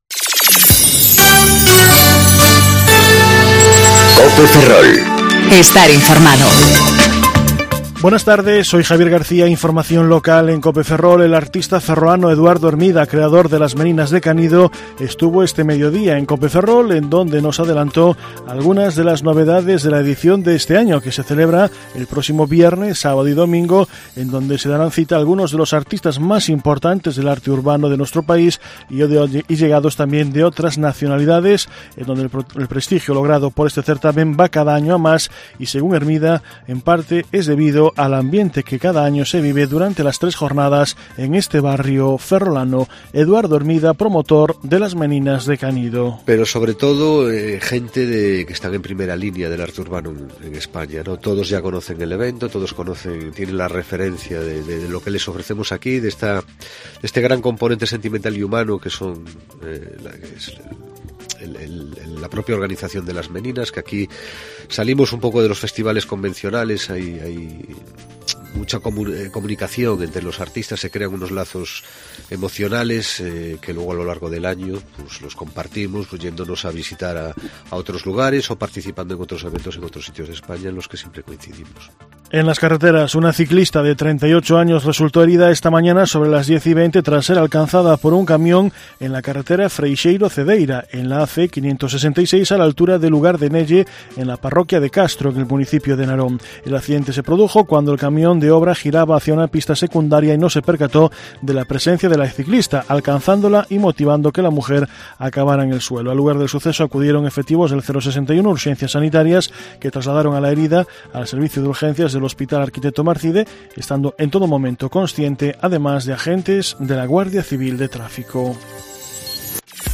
Informativo Mediodía Cope Ferrol - 23/08/2019 (De 14.20 a 14.30 horas)